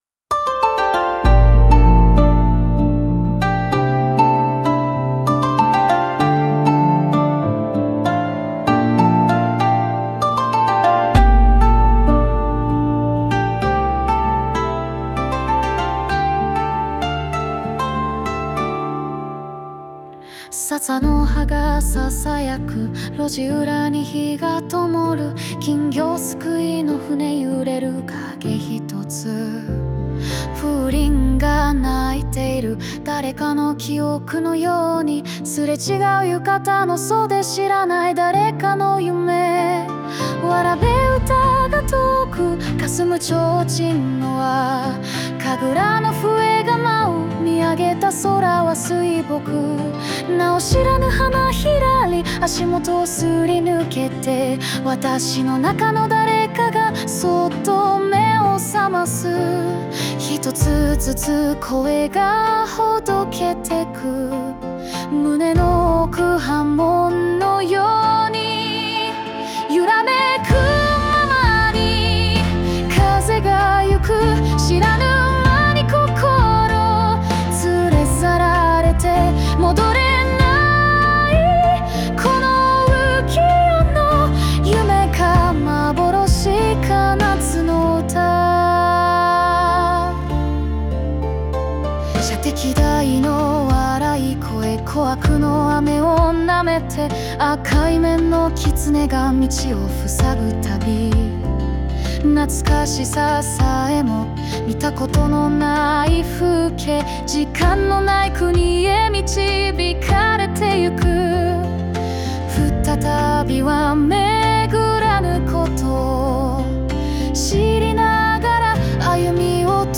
邦楽女性ボーカル著作権フリーBGM ボーカル
女性ボーカル（邦楽・日本語）曲です。
夏祭りの熱気と静けさ、現実と夢の境界線をたゆたうような・・・
どこか懐かしくて、でも誰の記憶とも言い切れない